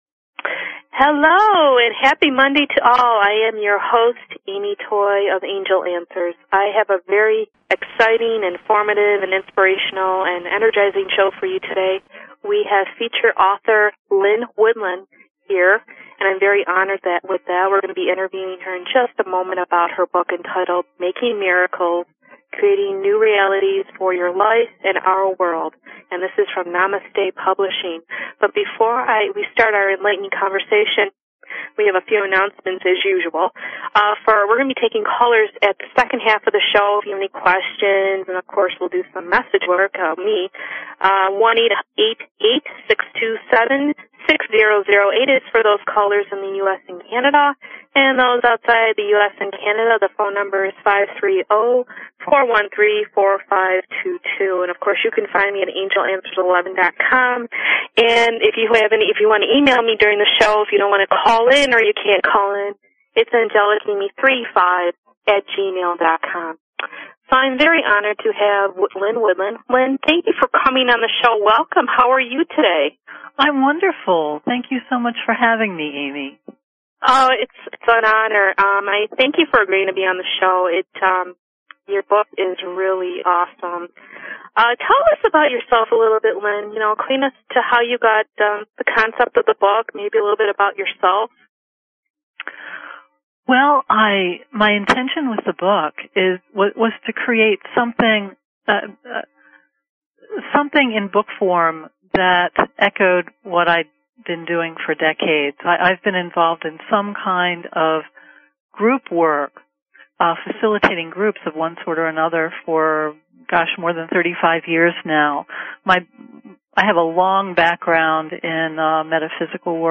Talk Show Episode, Audio Podcast, Angel_Answers and Courtesy of BBS Radio on , show guests , about , categorized as